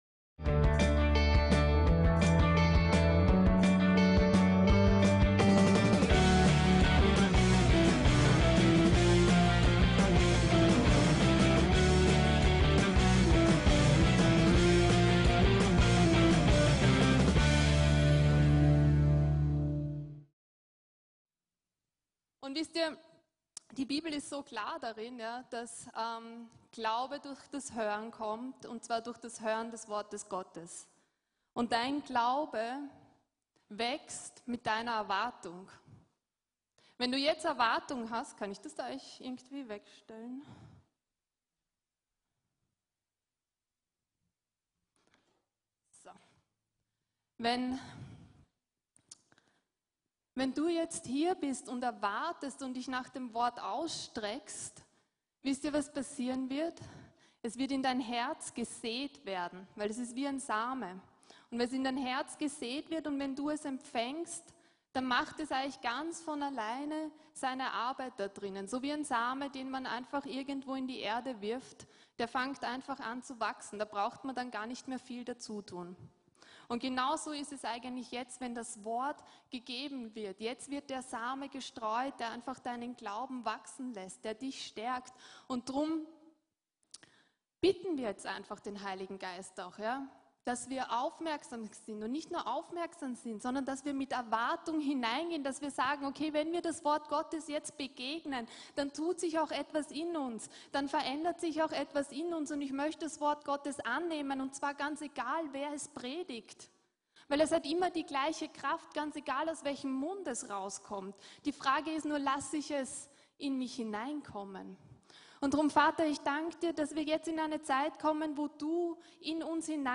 VCC JesusZentrum Gottesdienste